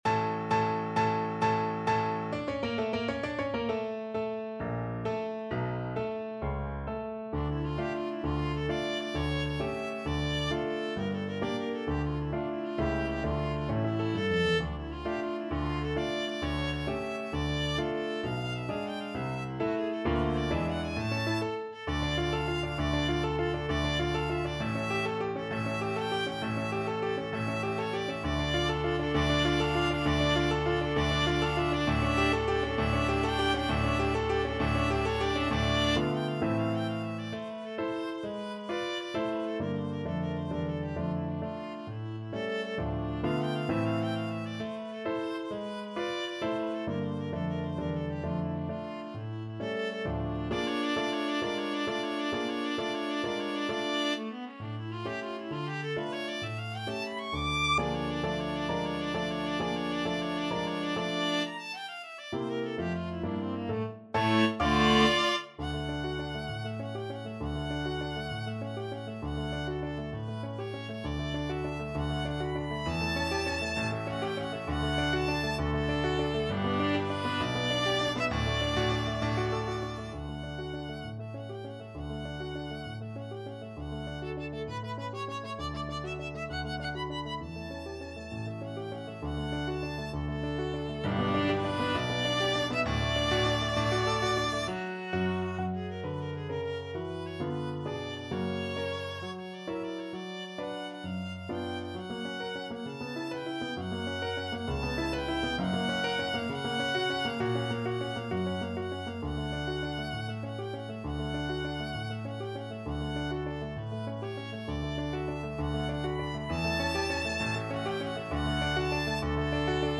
6/8 (View more 6/8 Music)
D4-D7
Classical (View more Classical Viola Music)